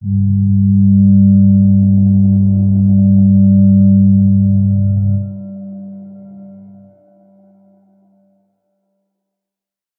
G_Crystal-G3-f.wav